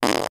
BODY_Fart_mono.wav